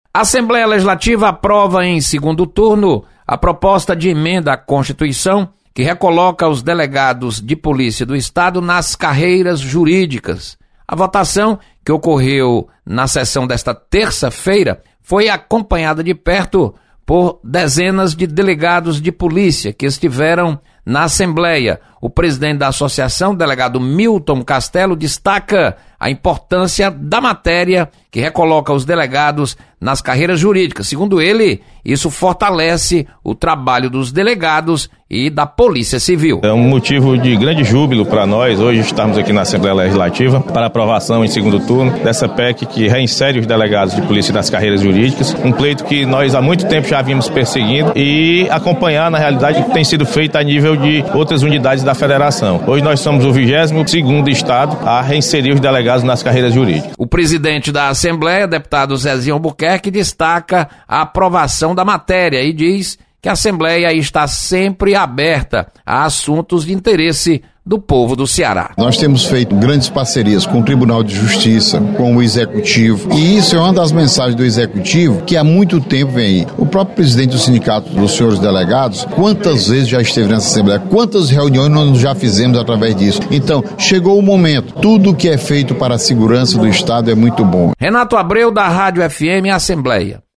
Você está aqui: Início Comunicação Rádio FM Assembleia Notícias Plenário